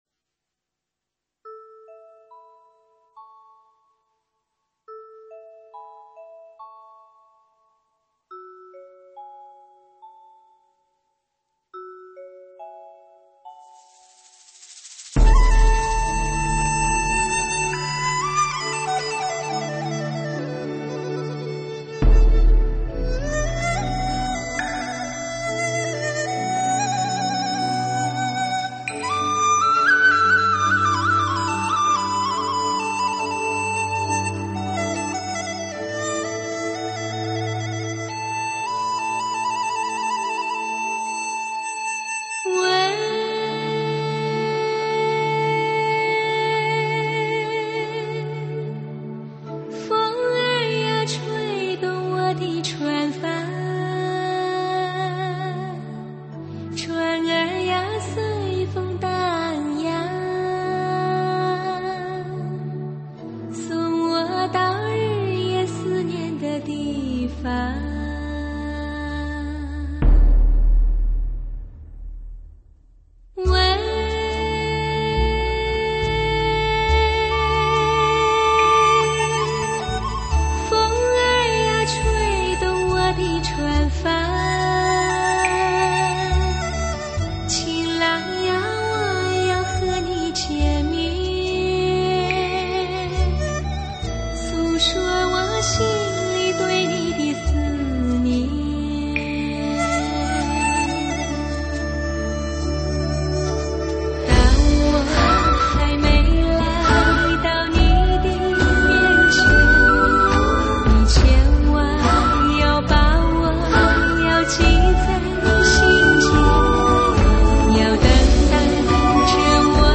音乐类型：国语流行